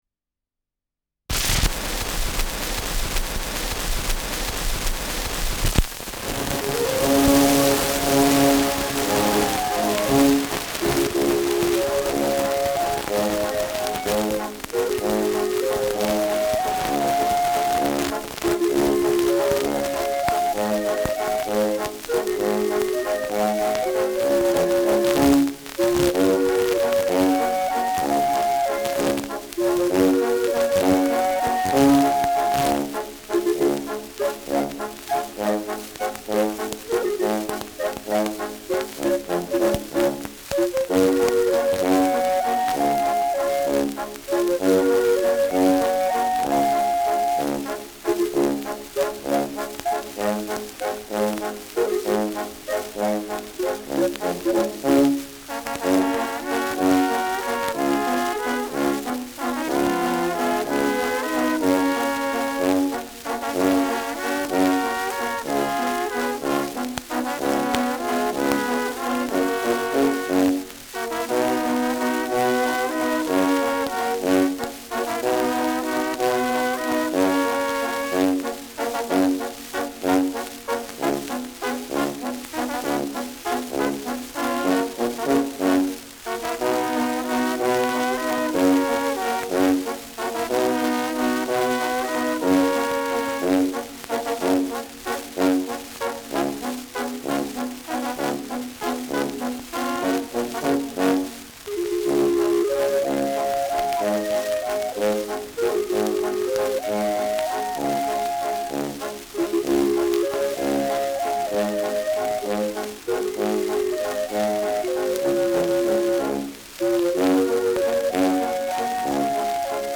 Schellackplatte
Besonders zu Beginn stark verrauscht : Durchgehend leichtes bis stärkeres Knacken : Abgespielt
Die gemüatlichen Münchener (Interpretation)